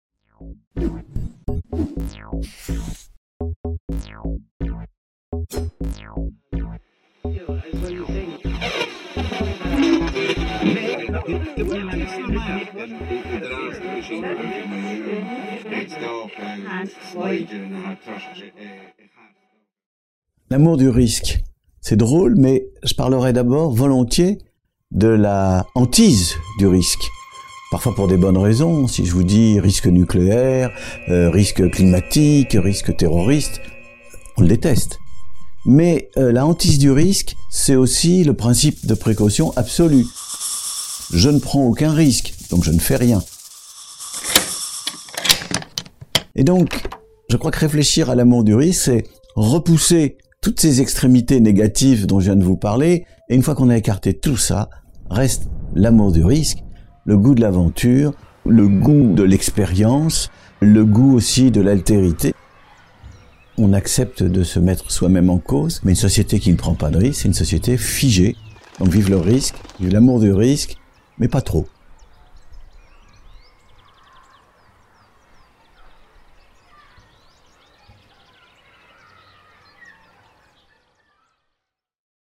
Michel Wieviorka, sociologue, Président de la FMSH, s'exprime dans le cadre du Festival des Idées 2017 sur le thème "l'amour du risque".